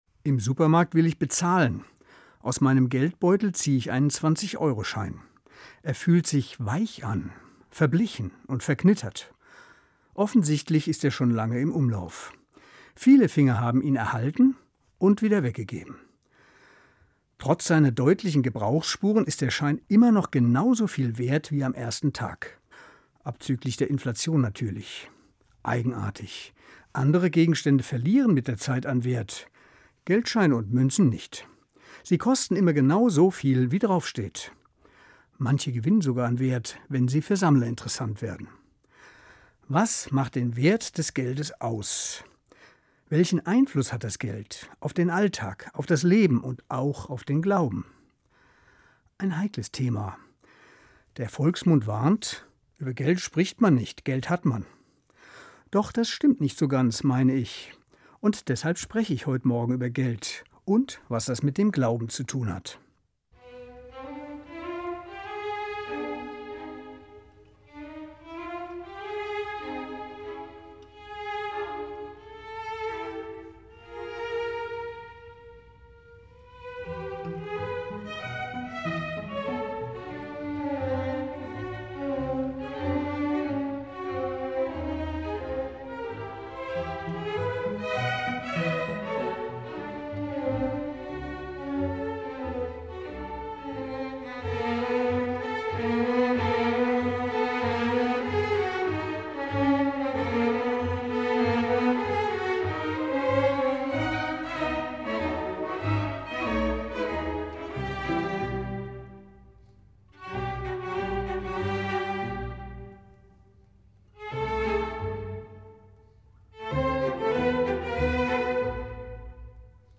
Was macht Geld wirklich wertvoll – und wie prägt es unseren Glauben? Eine Radio-Andacht über Geld, Vertrauen, biblische Texte und die Suche nach wahrem Reichtum.